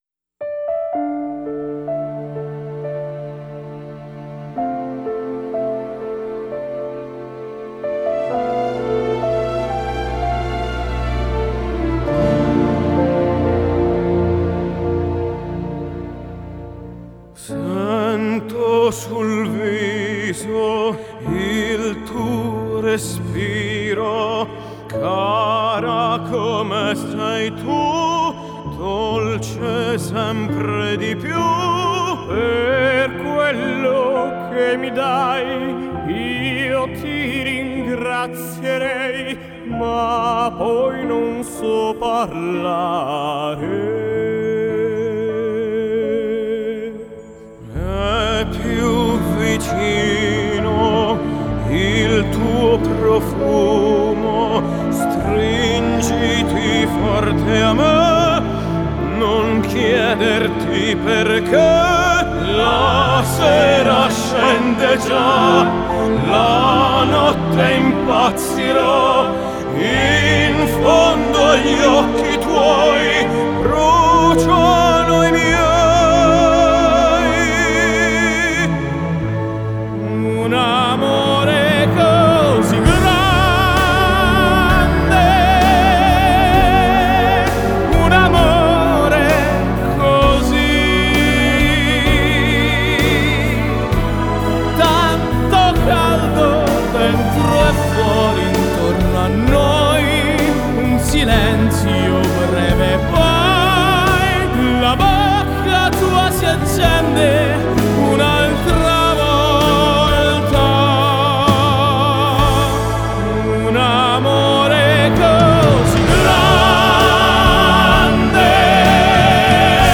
новое явление на поприще жанра классикал кроссовер.